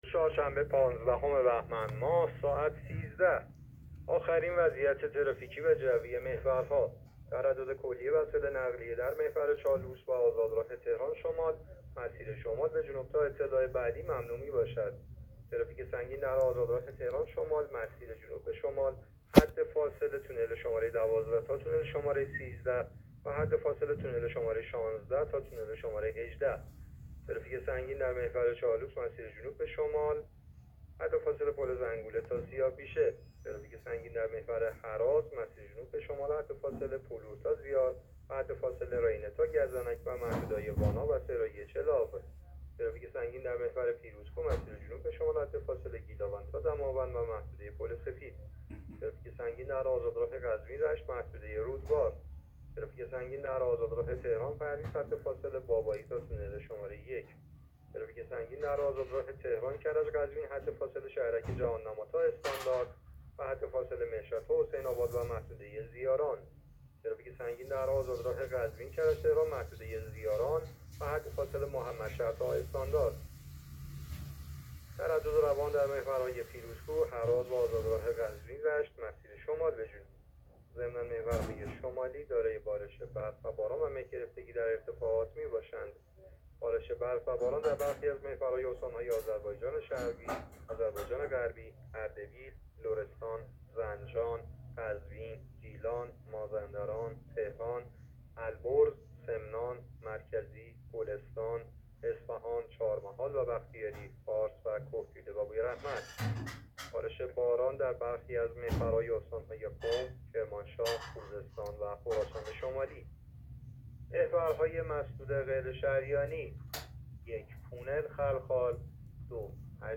گزارش رادیو اینترنتی از آخرین وضعیت ترافیکی جاده‌ها ساعت ۱۳ پانزدهم بهمن؛